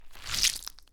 squish03.ogg